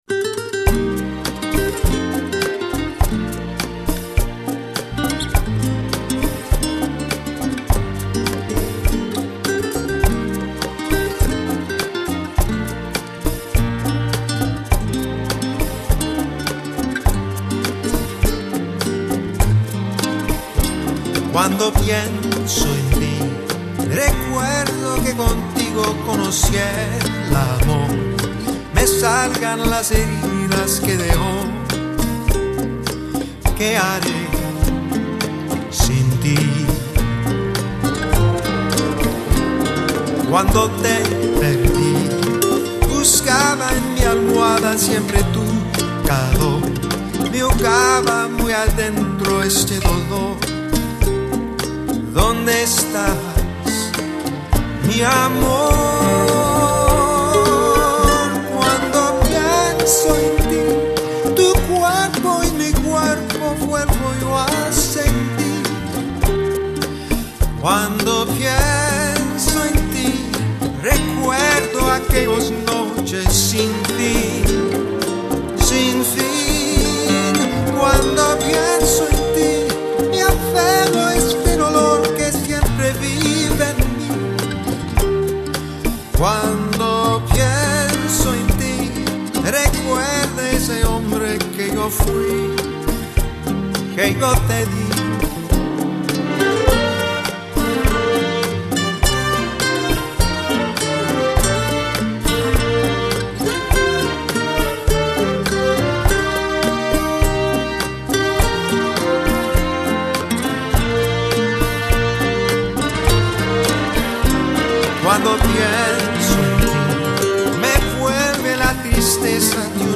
10 Rumba